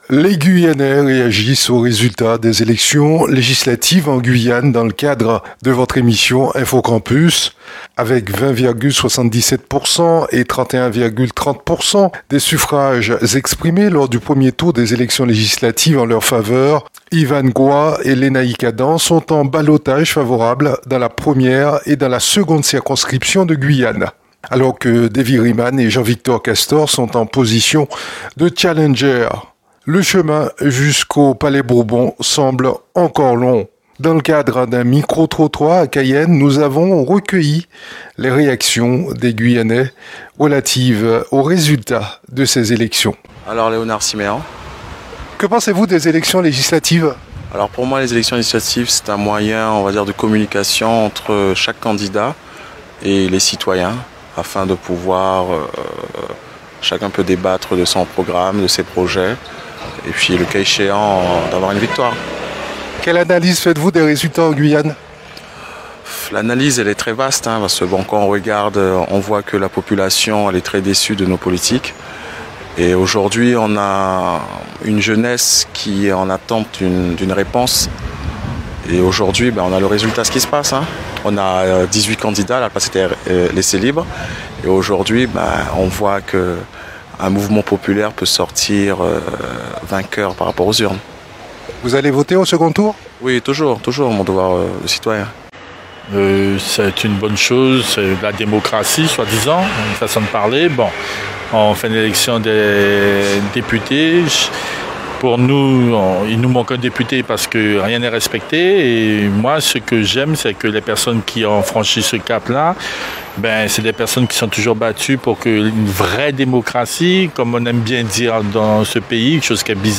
Dans le cadre d'un micro trottoir à Cayenne, nous avons recueilli les réactions des guyanais, relatives à ces élections.